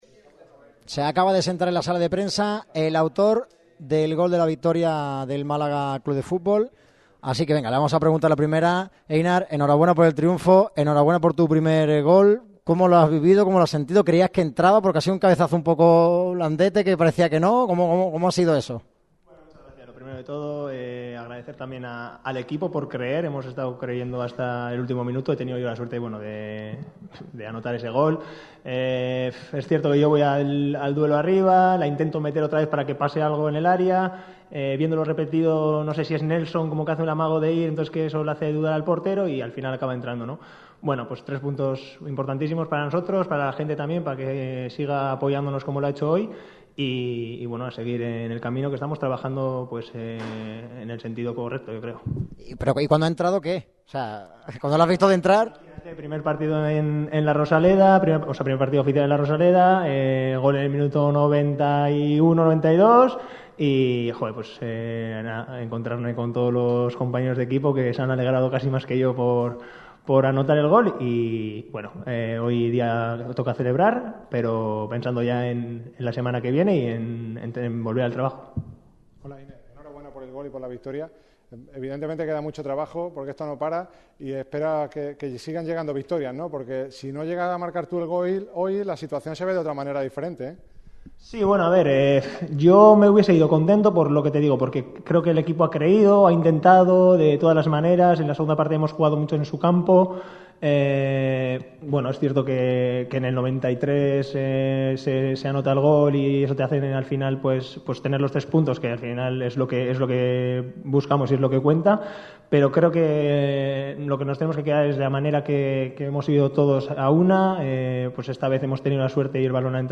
El central ha comparecido ante los medios tras la victoria del Málaga sobre el Atlético de Madrid B. El central ha resultado ser protagonista porque ha sido el encargado de anotar el gol de la victoria malaguista en el minuto 90.